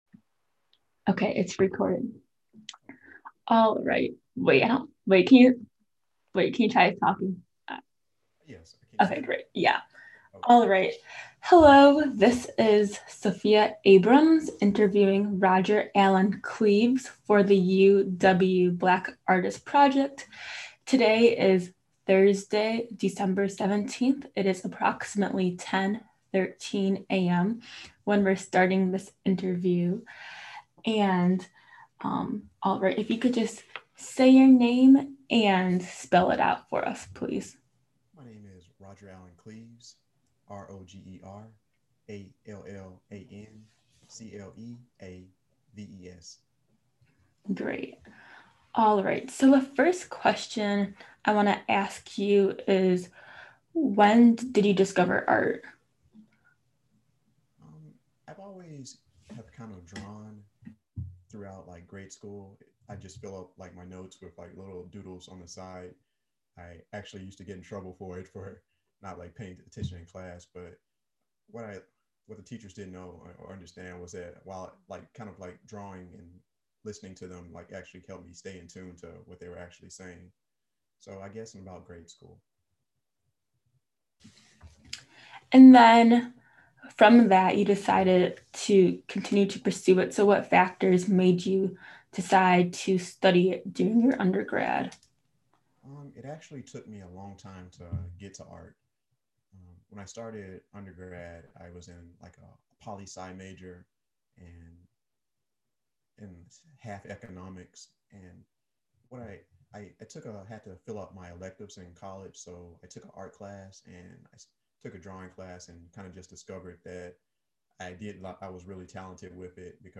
Oral History Program